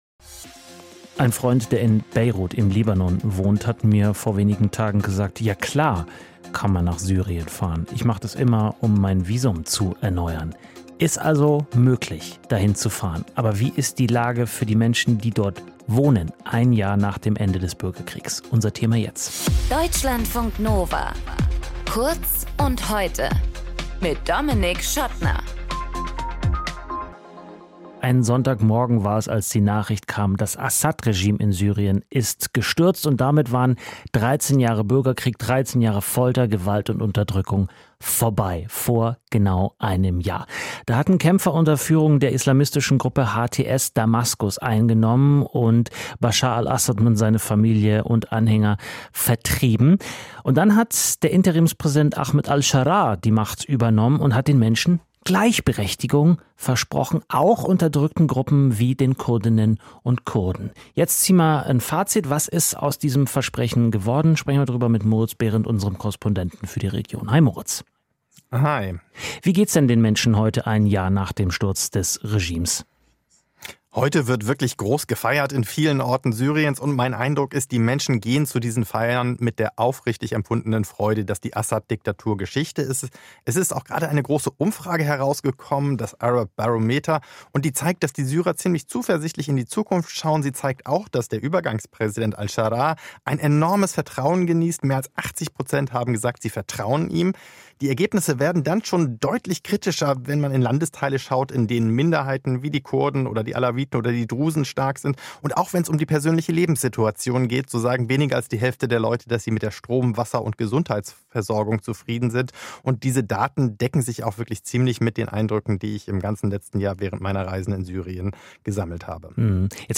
Moderation:
Gesprächspartner: